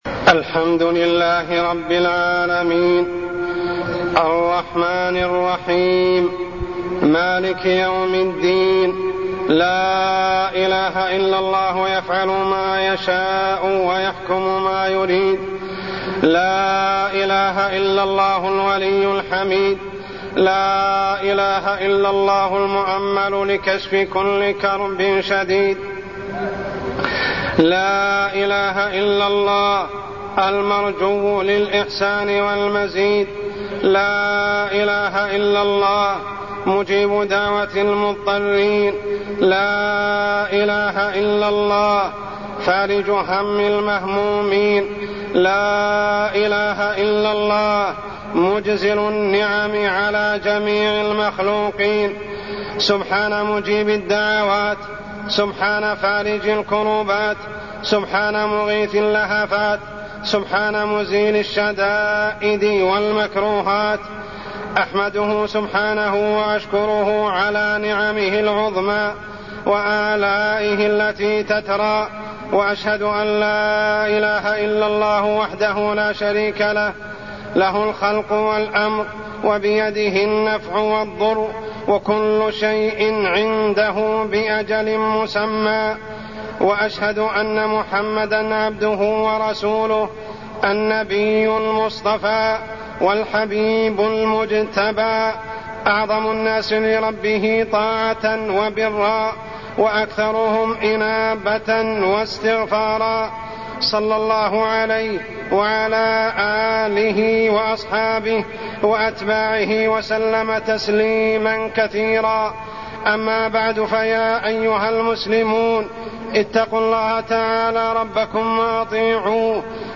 تاريخ النشر ١٢ رمضان ١٤٢٠ هـ المكان: المسجد الحرام الشيخ: عمر السبيل عمر السبيل الذنوب والمعاصي